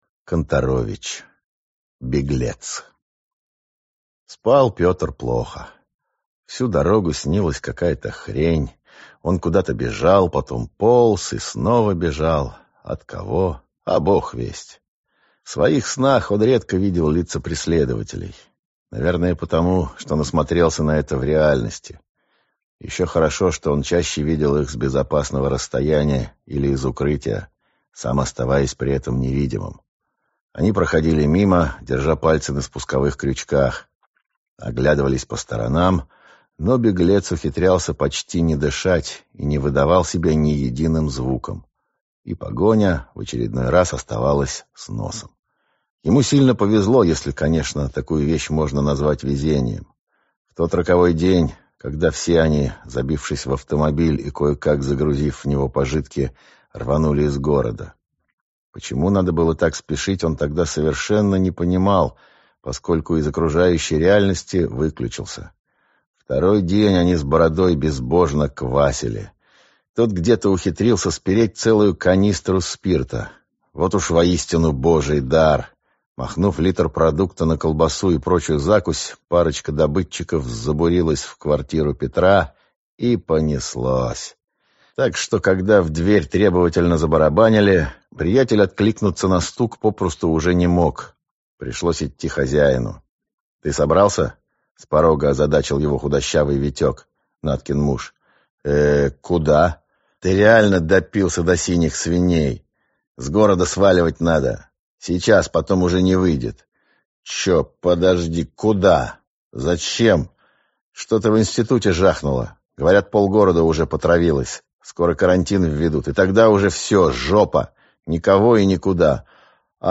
Читает аудиокнигу